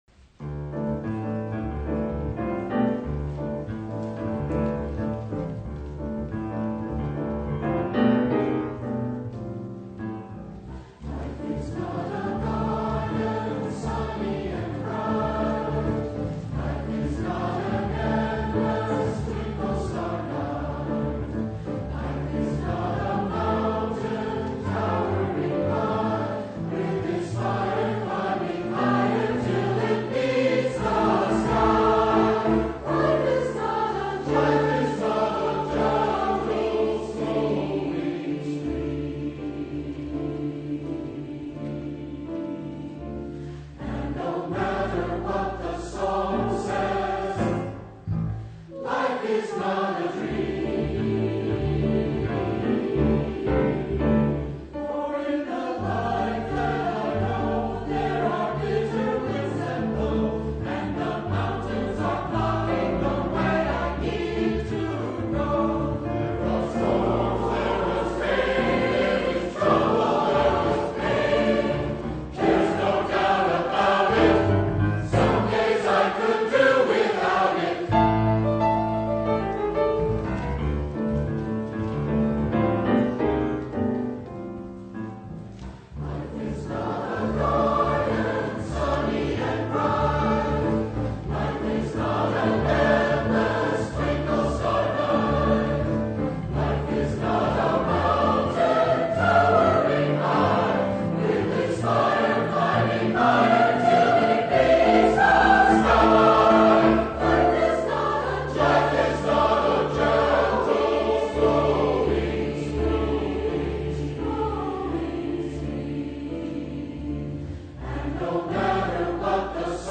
SATB, piano, opt. bass